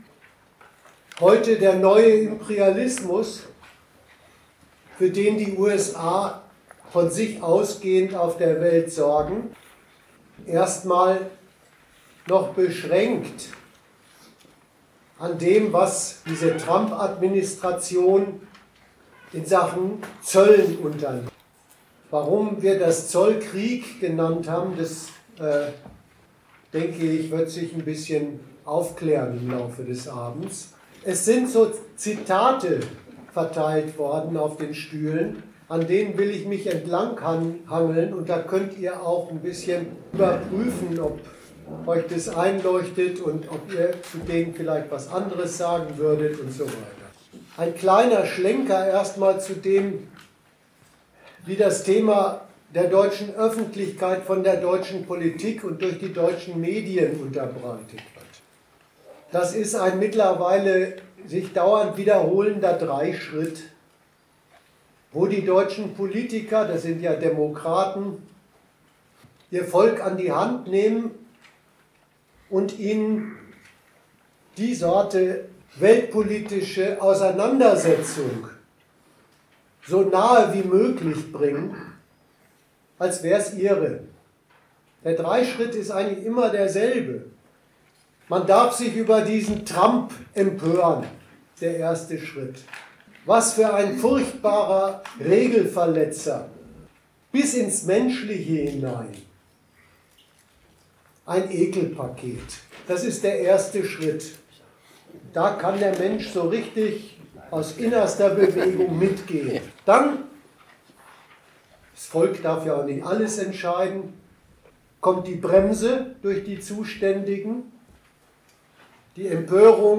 Darum will unser Vortrag auf die imperialistische Konsequenz aufmerksam machen, die in Trumps Bruch mit den Verkehrsformen und Rechnungsweisen der bisherigen Weltwirtschaftsordnung steckt. Das erpresserische Eintreiben von Tribut bei allen Handelspartnern ist seine Antwort auf den Widerspruch der globalen Konkurrenzordnung zu Nutz und Frommen des einen, sie stiftenden Konkurrenten.